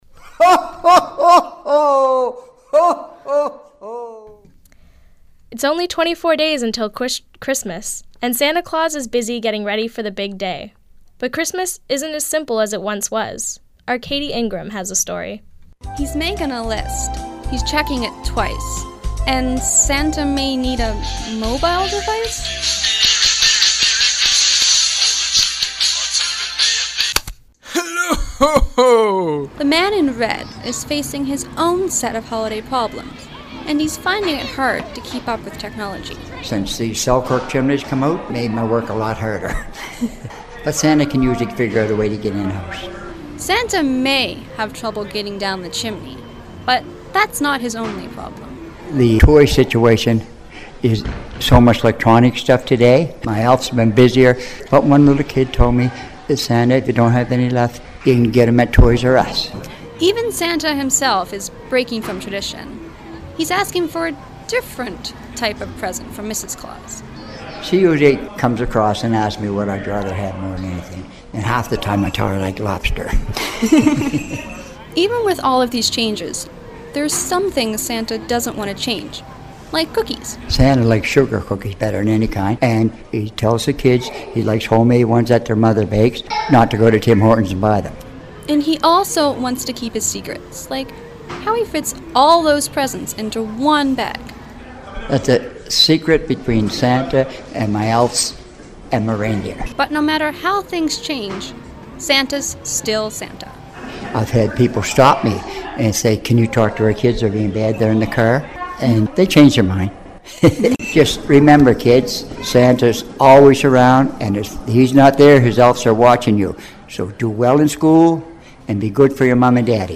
Santa has been delivering toys to children for years, but how have things changed for Kris Kringle? This was part of the Radio workshop at the University of King’s College that aired in 2010.